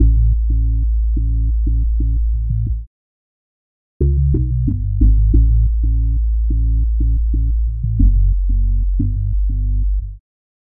标签： 90 bpm Electronic Loops Bass Loops 1.79 MB wav Key : Unknown
声道立体声